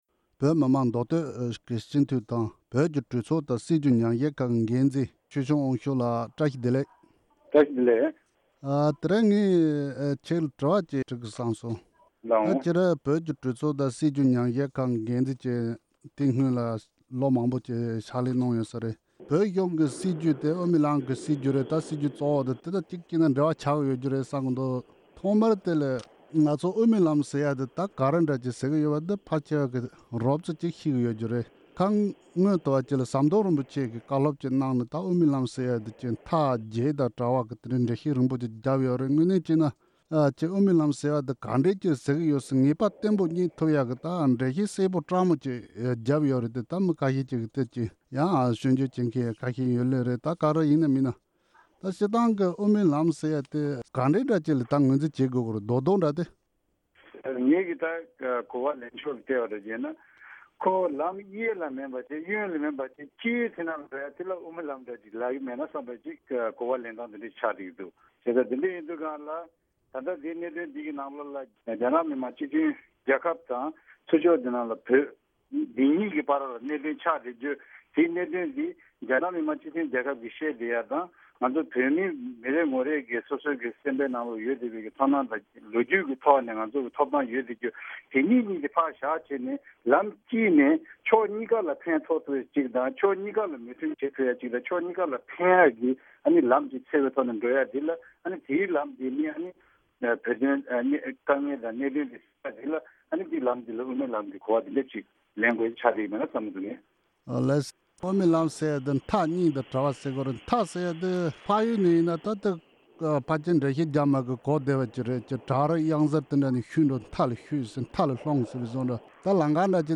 ད་ལྟའི་བཙན་བྱོལ་བོད་གཞུང་གི་སྲིད་ཇུས་ལངས་ཕྱོཊ་སྐོར་བོད་མི་མང་མདོ་སྟོད་སྤྱི་འཐུས་ཆོས་སྐྱོང་དབང་ཕྱུགས་ལགས་ཀྱིས་འགྲེལ་བརྗོད་གནང་བ།